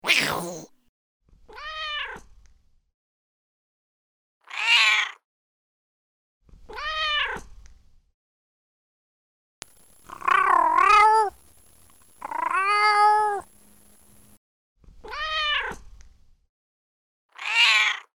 Cat Sound_mixdown.mp3